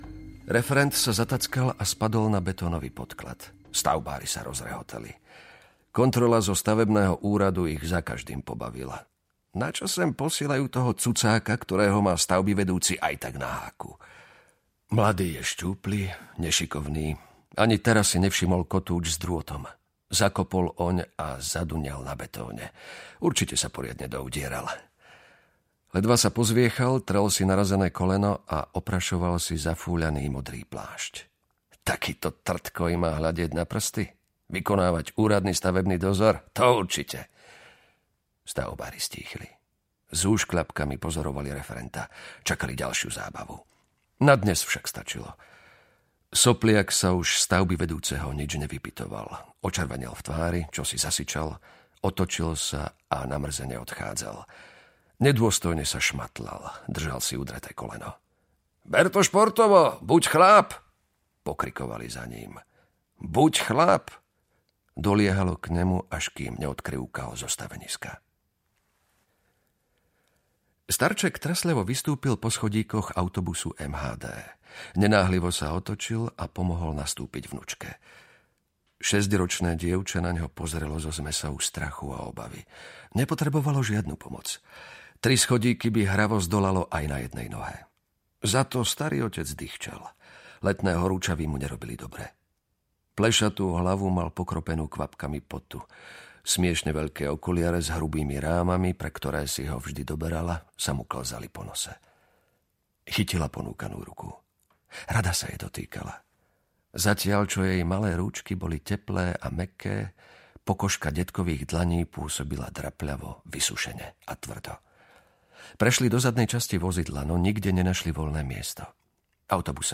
Ukázka z knihy
cierna-hra-vlada-mafie-audiokniha